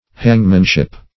hangmanship - definition of hangmanship - synonyms, pronunciation, spelling from Free Dictionary
Hangmanship \Hang"man*ship\, n.